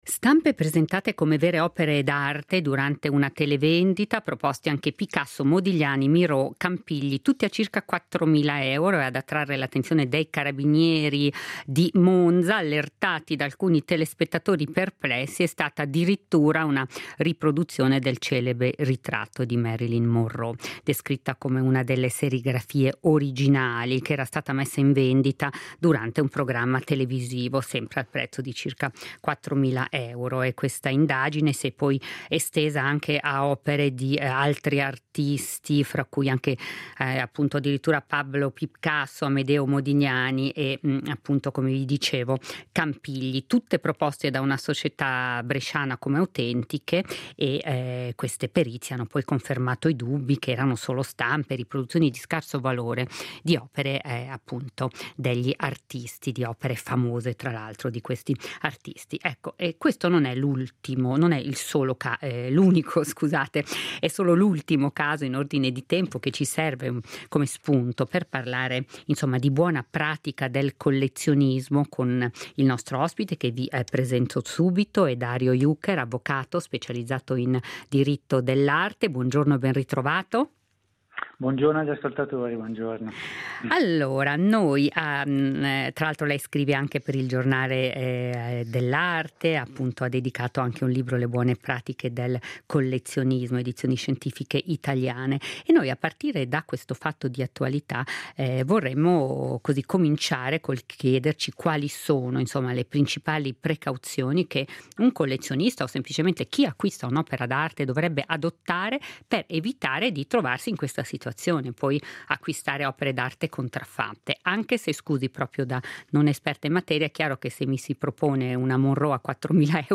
avvocato specializzato in diritto dell’arte